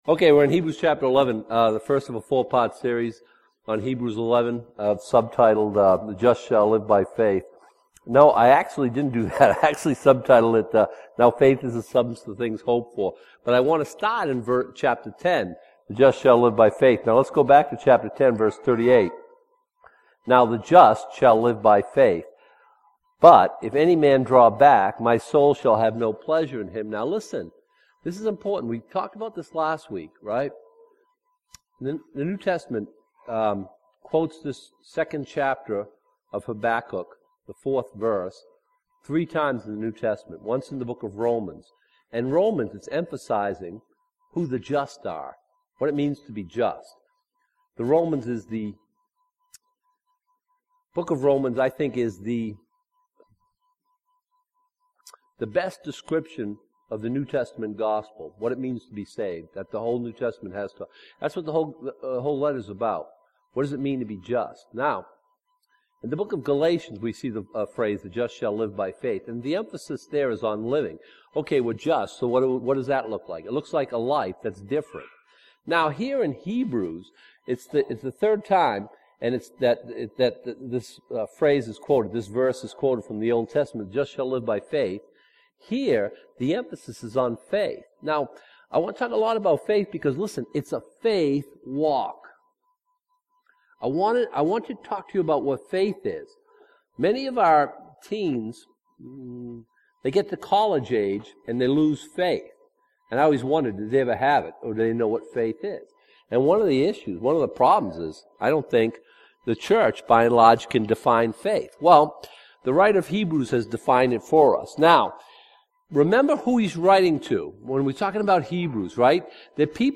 Teachings in Hebrews